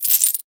03_get_drop_gold_01.wav